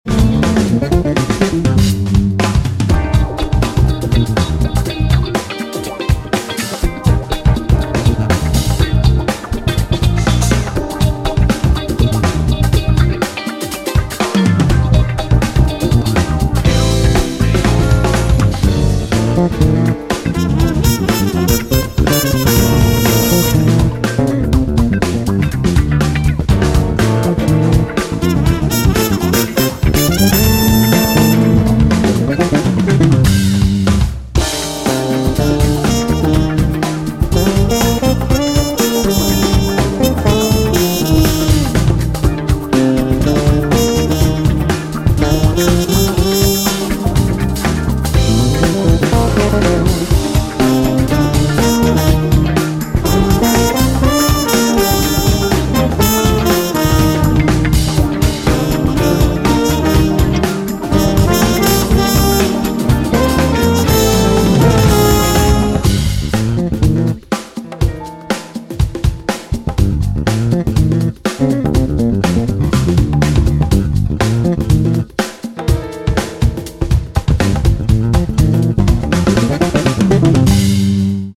Guitar, Keyboards, Programming
Trumpet, Flugelhorn
Drums
Electric Bass